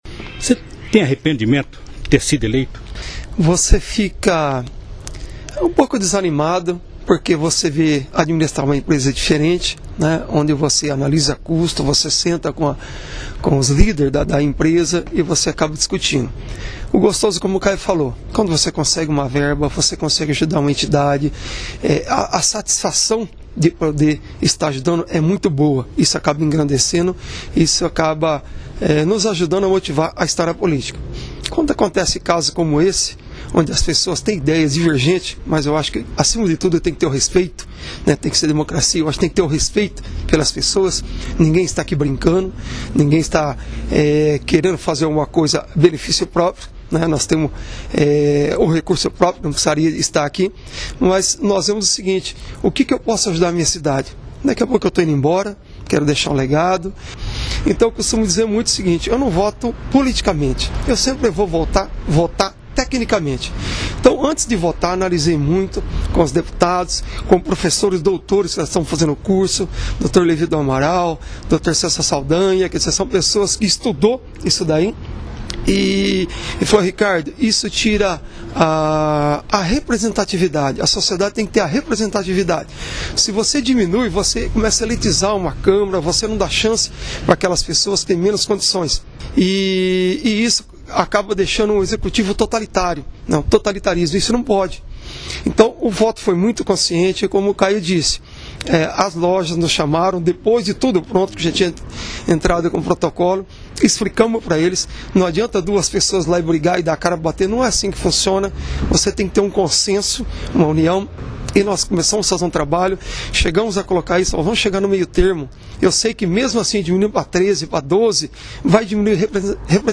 Os vereadores Caio Aoqui (PSDB) e José Ricardo Raymundo (PV), explicaram em entrevista à Rádio Cidade FM (91,5) o motivo que os levaram a retirar as assinaturas da emenda apresentada pelo vereador Danilo Aguillar Filho (PMDB).